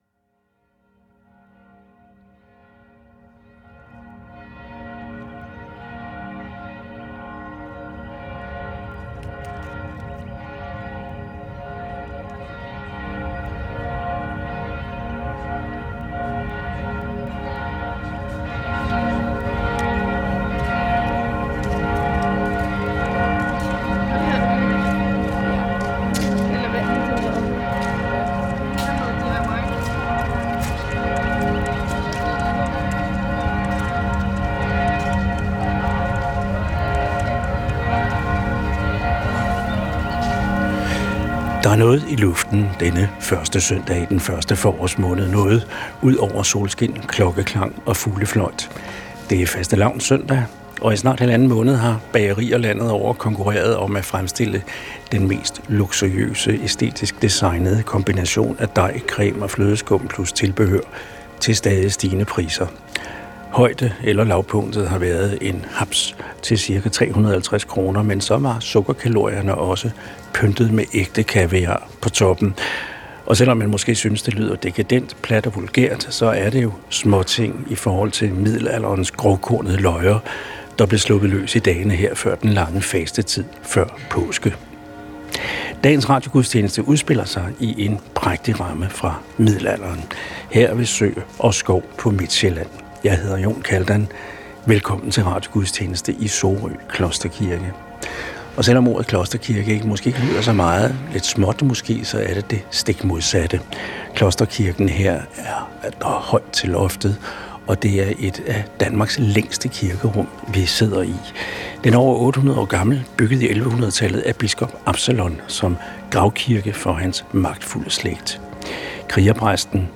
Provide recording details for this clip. Direkte transmission af dagens højmesse fra en af landets kirker.